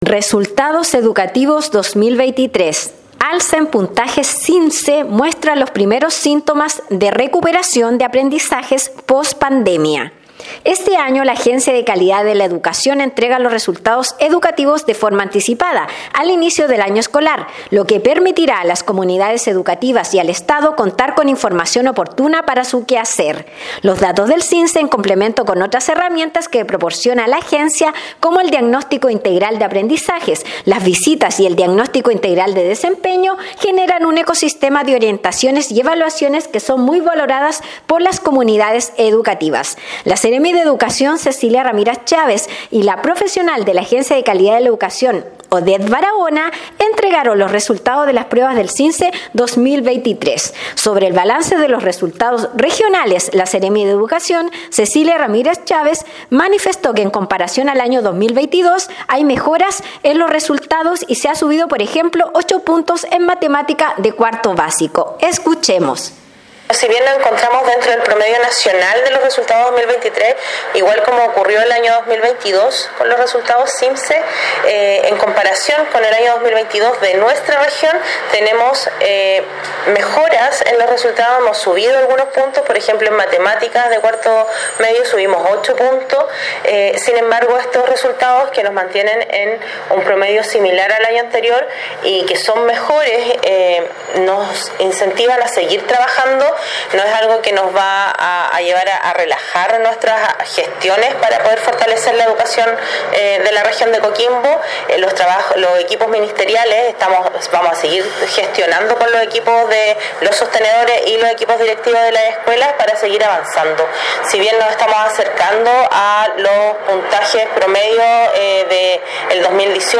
Despacho-Radial-Alza-en-puntajes-SIMCE-muestra-los-primeros-sintomas-de-recuperacion-de-aprendizajes-pospandemia_.mp3